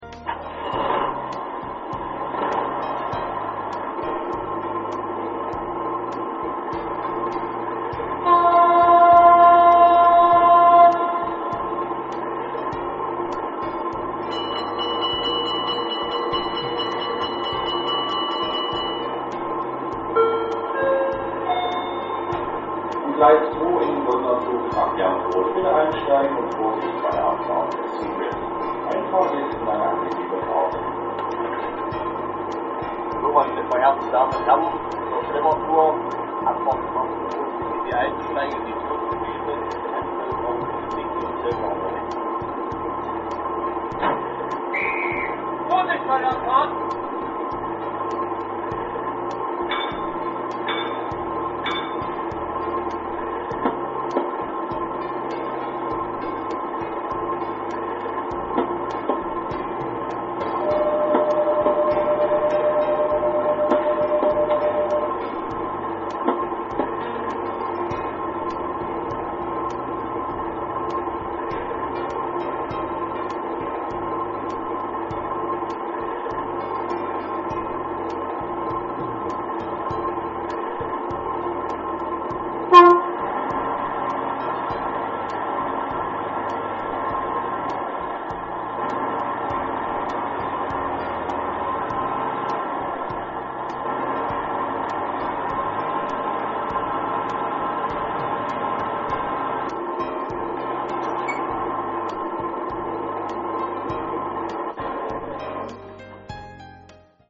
Hierzu werden Soundaufnahmen direkt am Vorbild vorgenommen und dann im Soundlabor für die Elektronik abgeglichen.
• Einschaltphase
• Standgeräusch
• Fahrgeräusch
Die Hintergrundmusik in den MP3-Demo Dateien ist nicht im XLS-Modul vorhanden!
Soundgeräusch